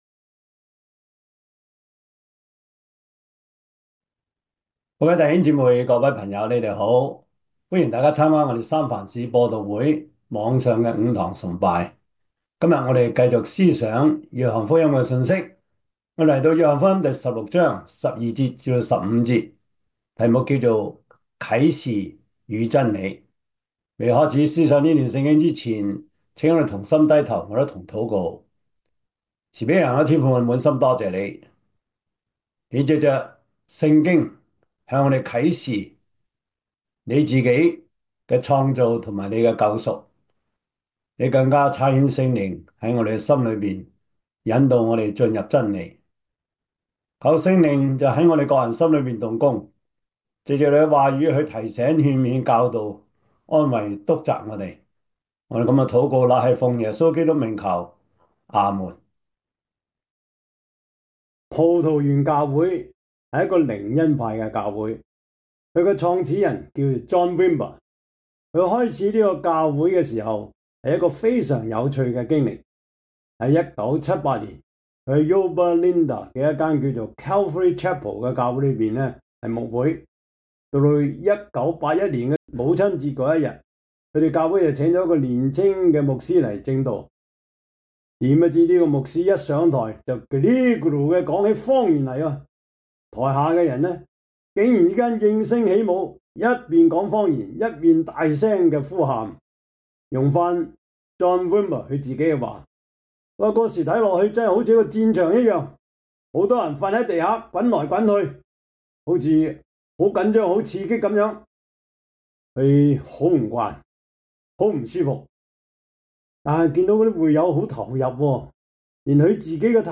約翰福音 16:12-15 Service Type: 主日崇拜 約翰福音 16:12-16 Chinese Union Version